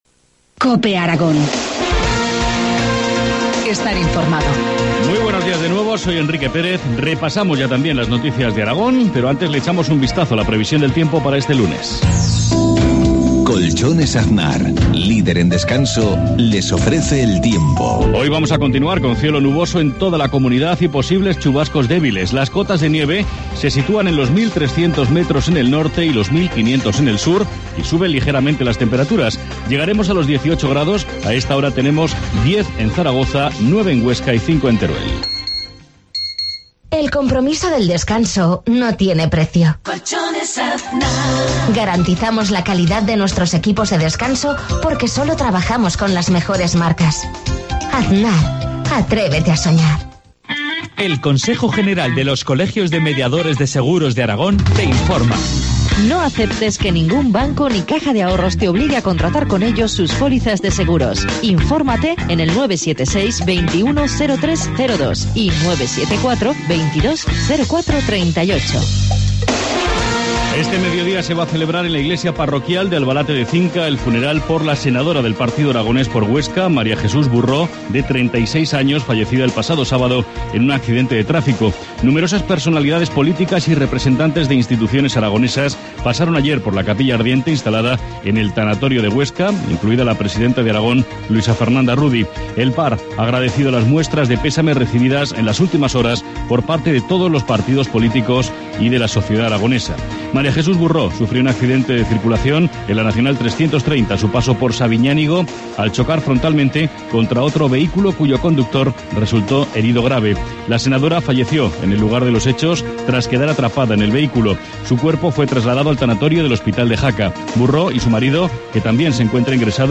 Informativo matinal, lunes 20 de mayo, 7.53 horas